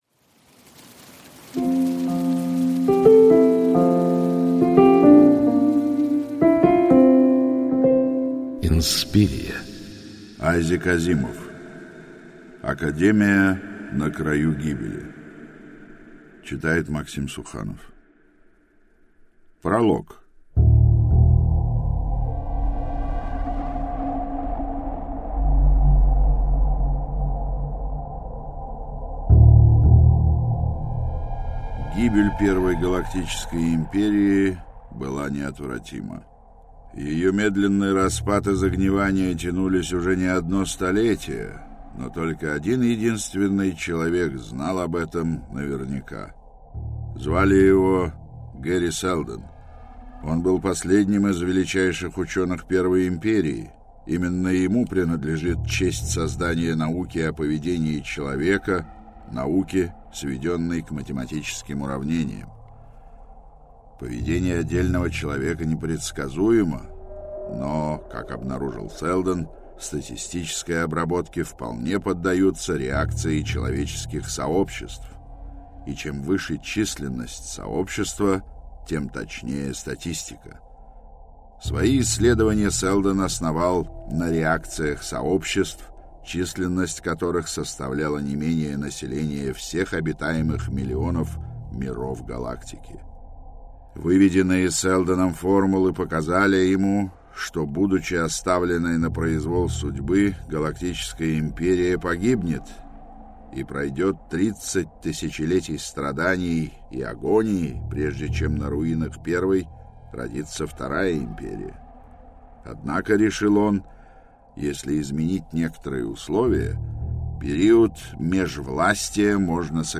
Аудиокнига Академия на краю гибели | Библиотека аудиокниг